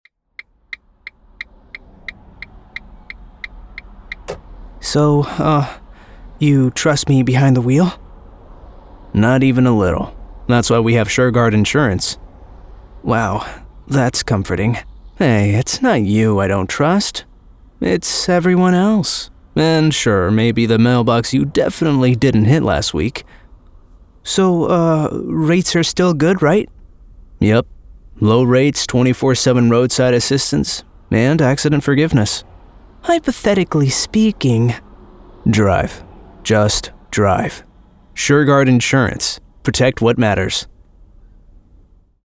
Conversational, Funny, Laid Back